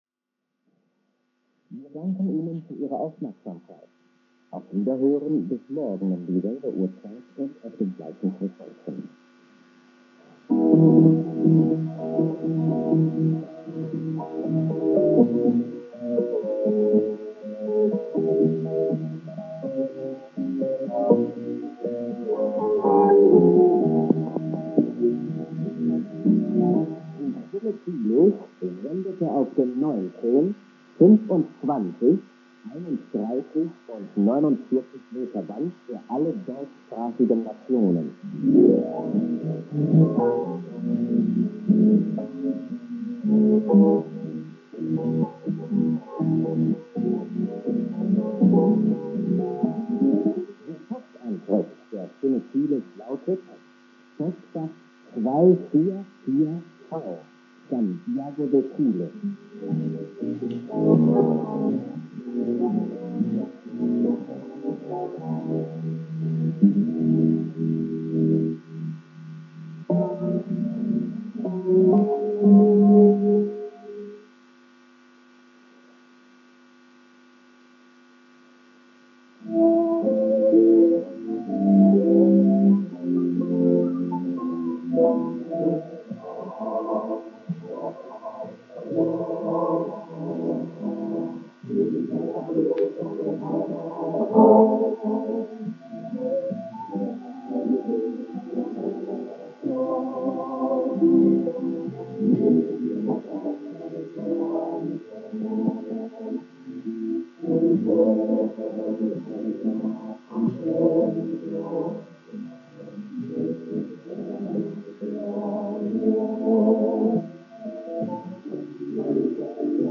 Stimme Chiles Santiago / CHL 15150 kHz - Empfangsort Schwäbisch Gmünd 1978 - Satellit 2000
Rx, Ant: Grundig Satellit 2000 - 30m Langdraht
SINPO: 44433